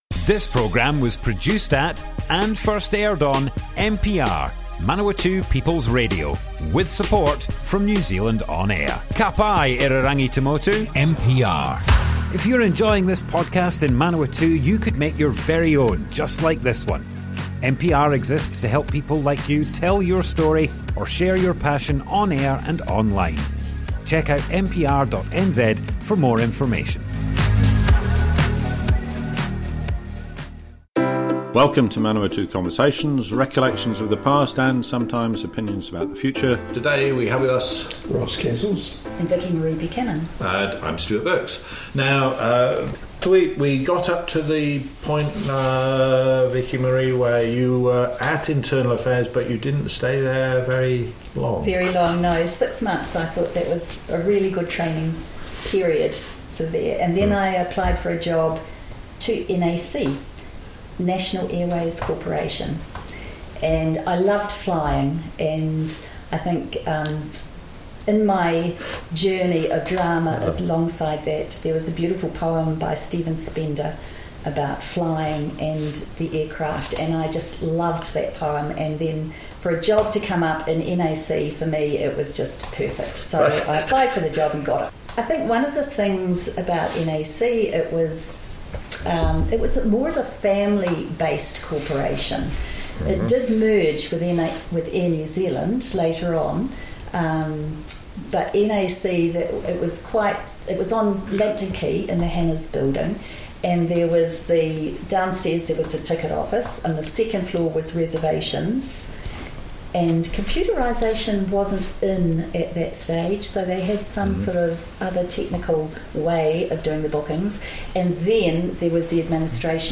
Manawatu Conversations Object type Audio More Info → Description Broadcast on Manawatu People's Radio, 18th June 2024.
oral history